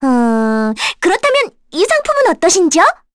Requina-Vox_Skill6_kr.wav